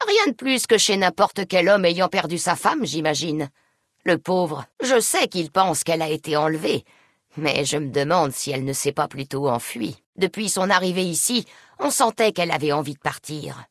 Dialogue audio de Fallout: New Vegas